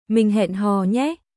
Mình hẹn hò nhéミン ヘン ホー ニェーデートしよう